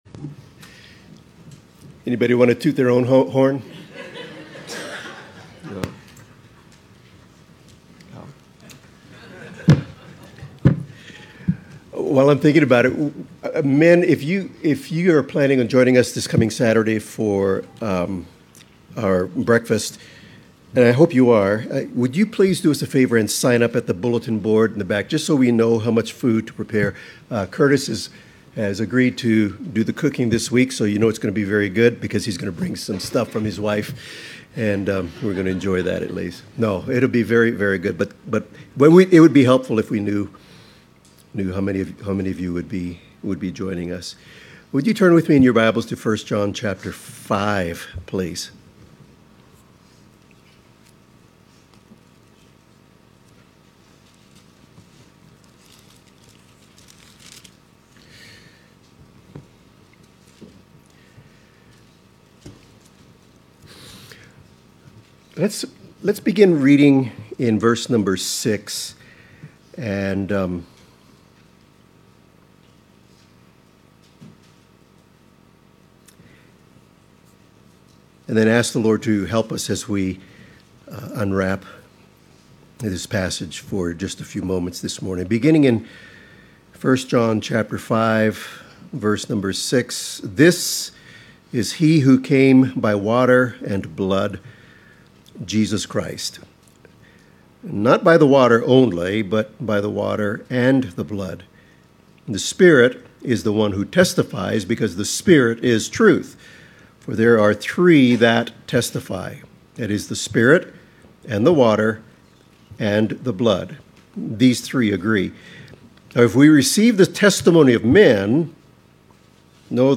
1 John 5:13 Service Type: Morning Service « Leviticus 03/03/24 Our Confidence in Christ